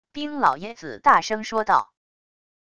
冰老爷子大声说道wav音频